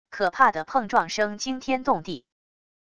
可怕的碰撞声惊天动地wav音频